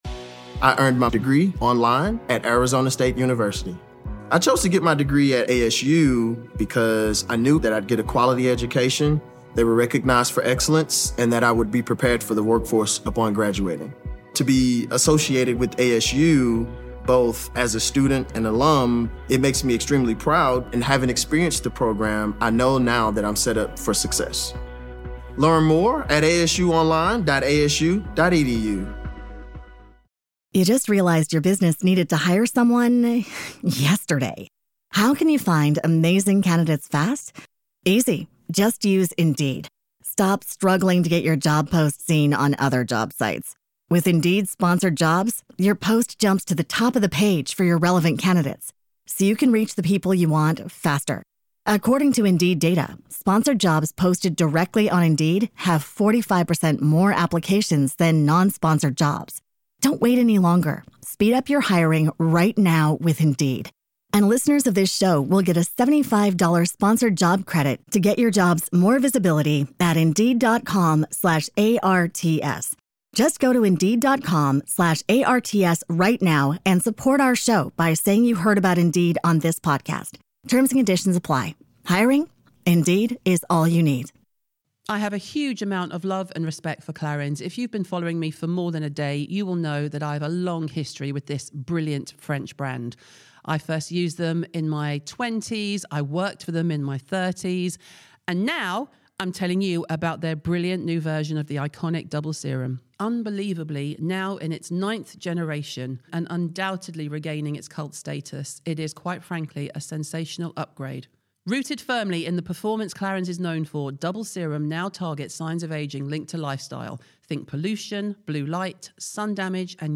JAMIE GENEVIEVE INTERVIEW